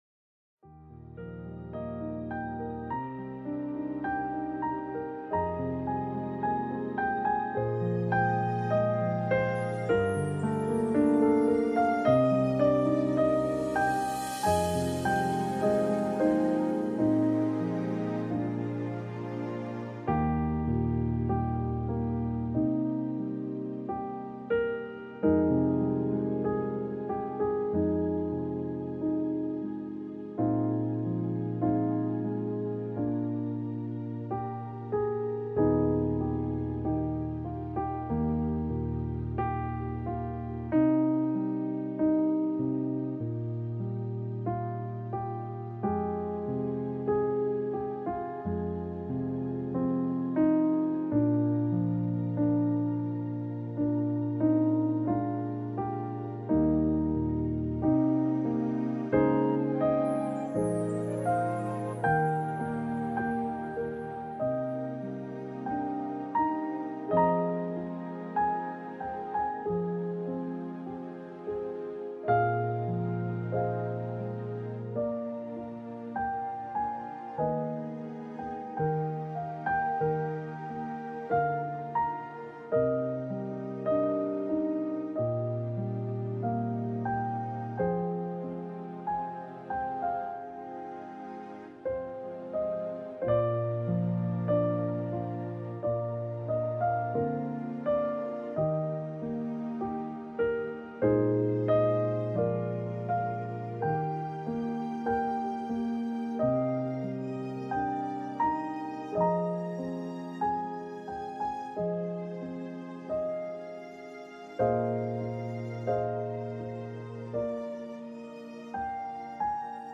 For a relaxing experience while you are engaged in a scripture rich study, click the play button on the music player below.
praisepiano2-1.mp3